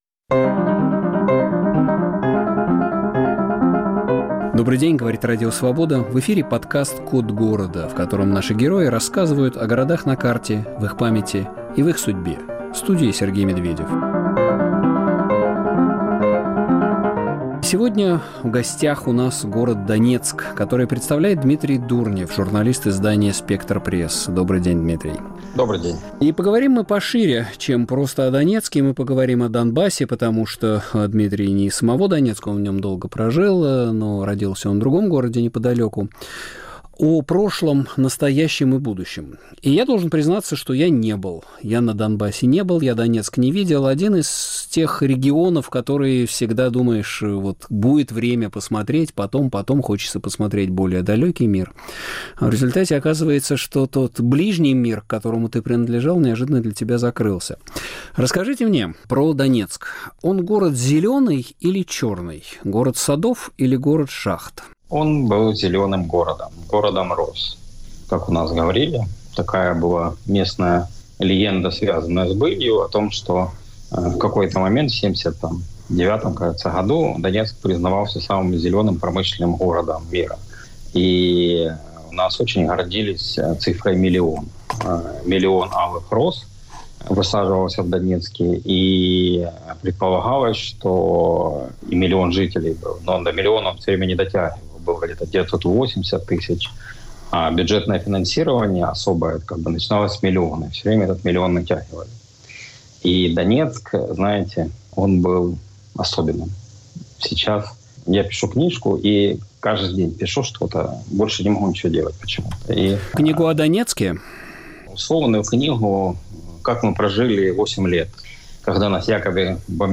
Повтор эфира от 26 августа 2024 года.